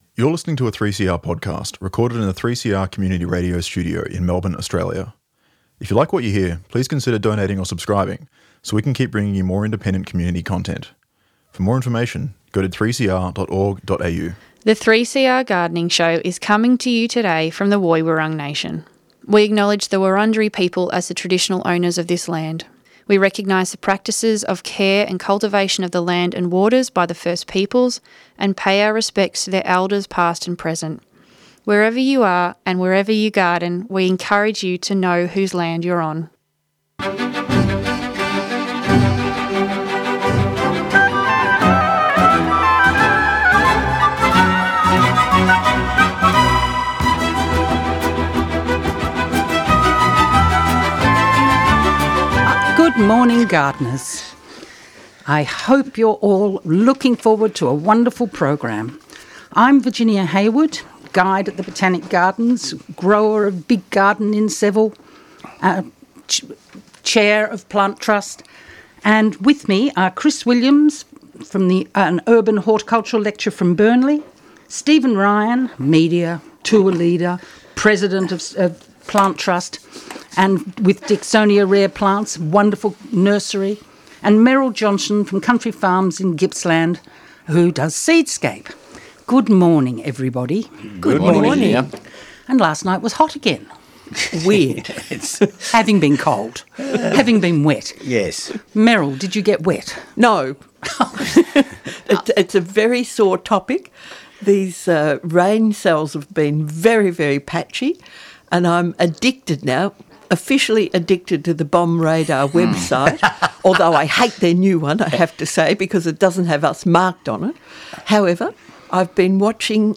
Tweet Gardening Show Sunday 7:30am to 9:15am Engaging panel discussions and Q &A addressing a wide range of gardening, urban horticulture and environmental issues.